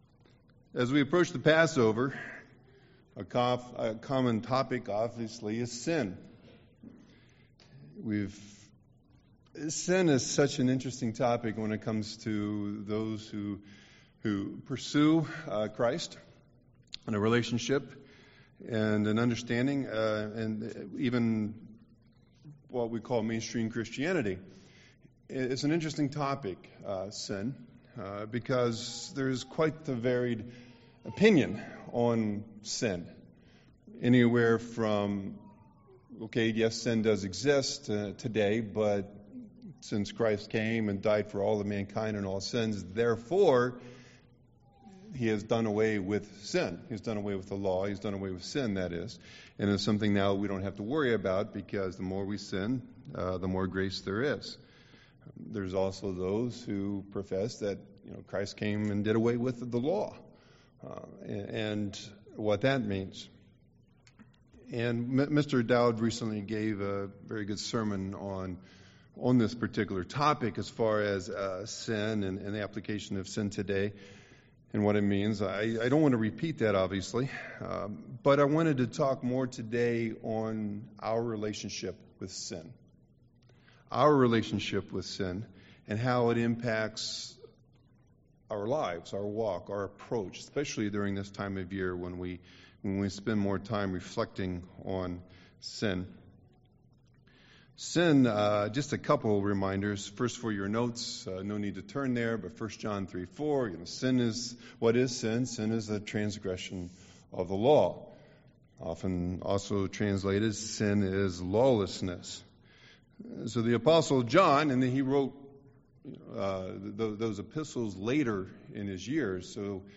Given in Milwaukee, WI
UCG Sermon sin relationship interaction effects Passover Studying the bible?